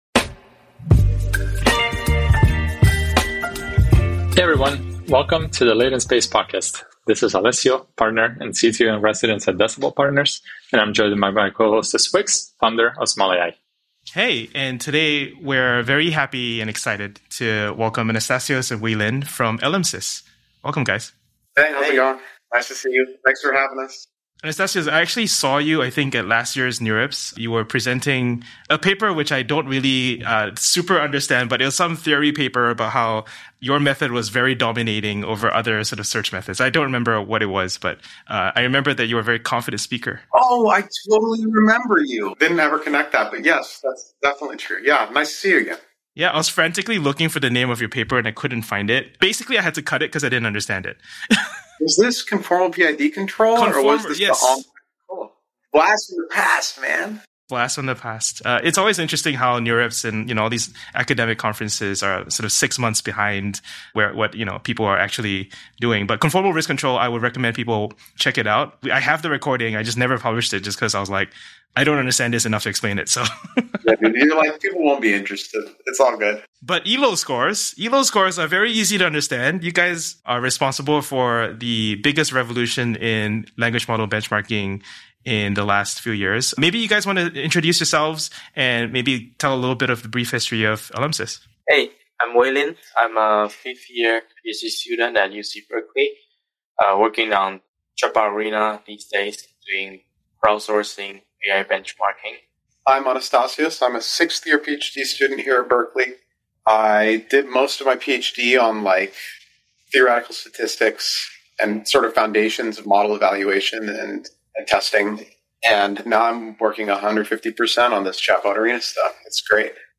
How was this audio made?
Apologies for lower audio quality; we lost recordings and had to use backup tracks.